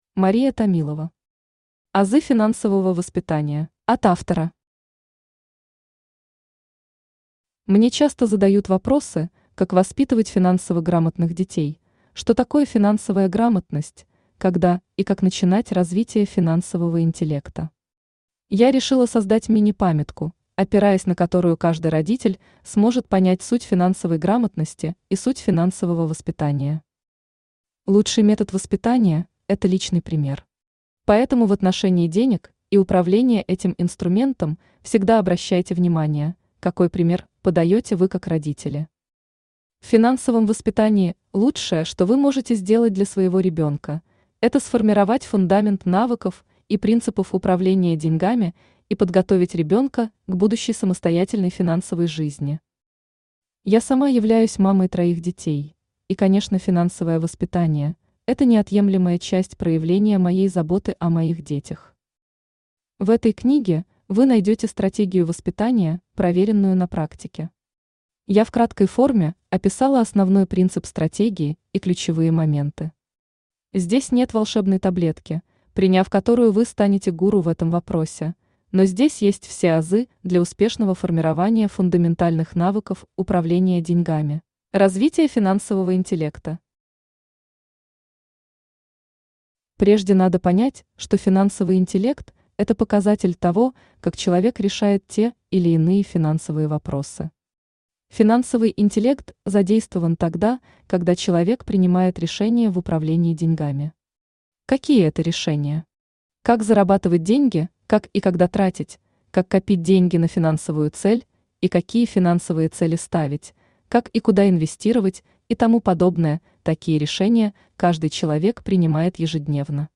Аудиокнига Азы финансового воспитания | Библиотека аудиокниг
Aудиокнига Азы финансового воспитания Автор Мария Николаевна Томилова Читает аудиокнигу Авточтец ЛитРес.